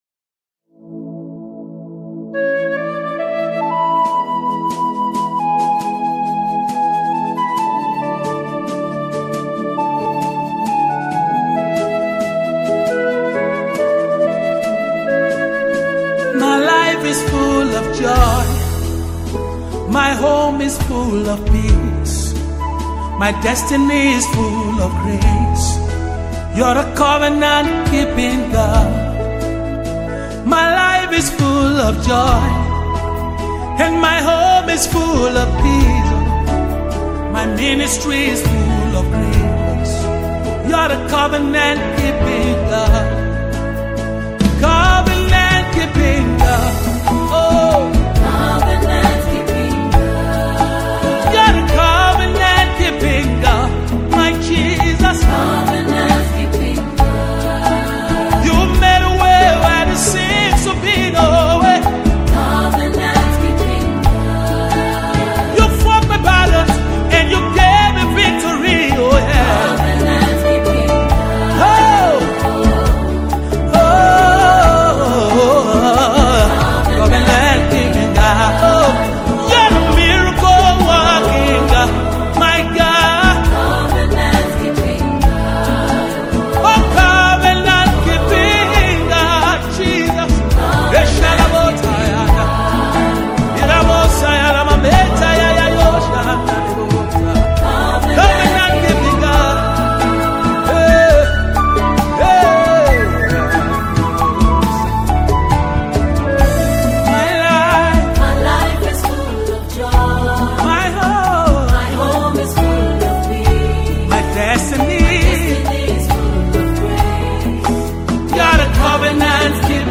gospel music
spirit-filled song